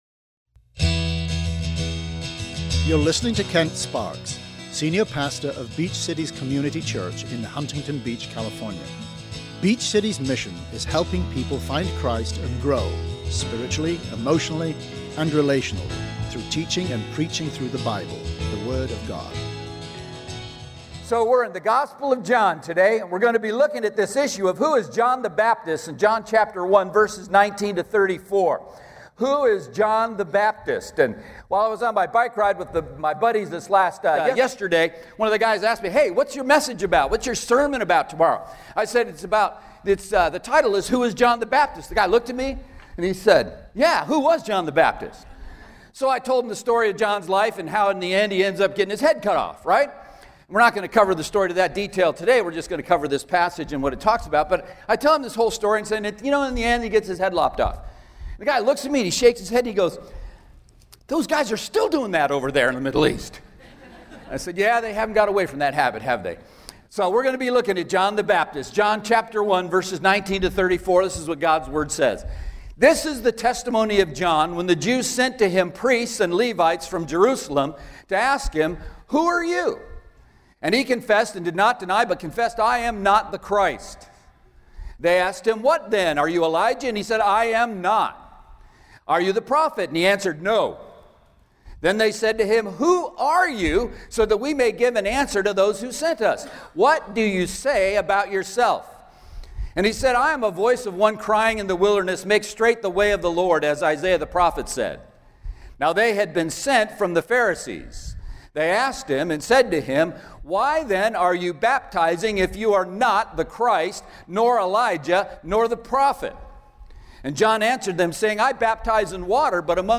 SERMON AUDIO: SERMON NOTES: